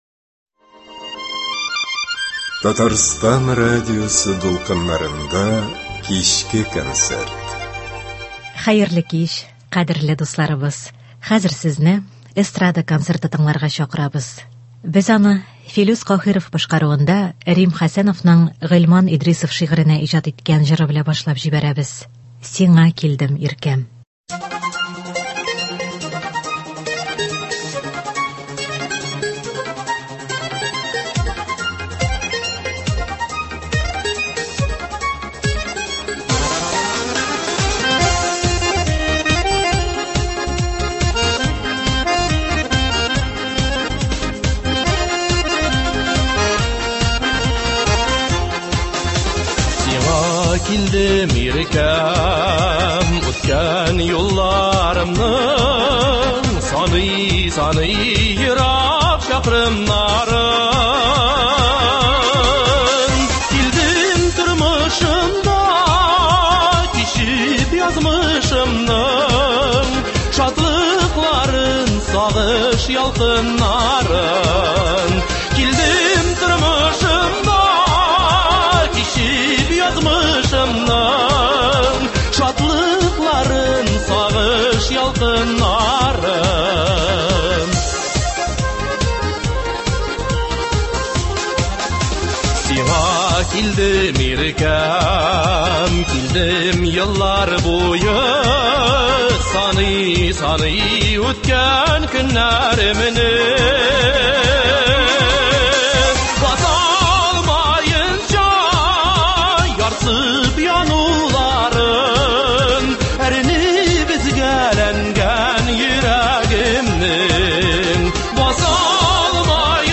Эстрада музыкасы концерты.